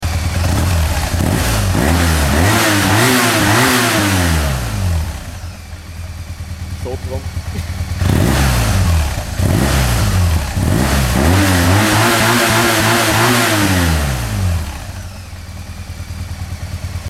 Am originalen BMW Auspuff gibt es eigentlich nichts auszusetzen, außer eben, dass er doch sehr leise ist und wenn man schon immer am "herbrennen" hochgetunter und gepimpter Japanischer Bikes ist, sollten diese dann auch noch eine akustische Ohrfeige bekommen:
Auspuff Original
auspuff_original.mp3